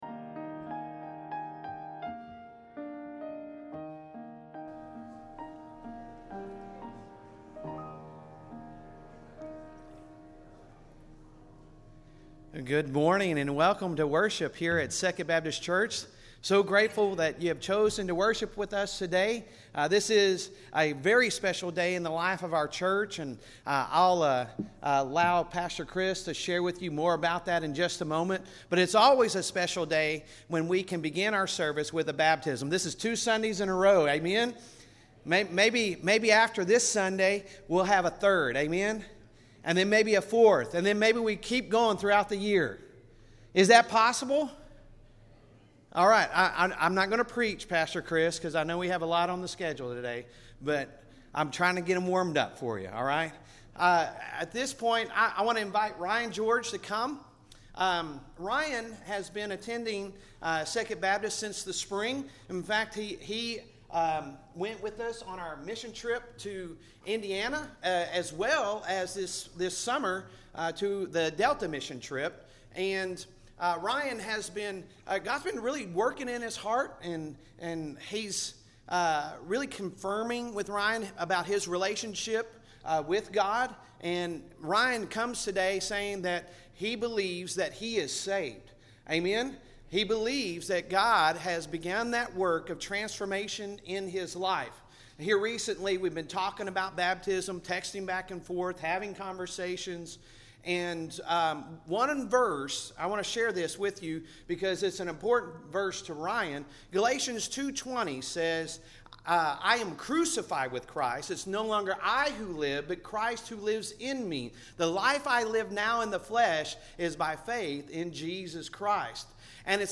Sunday Sermon August 27, 2023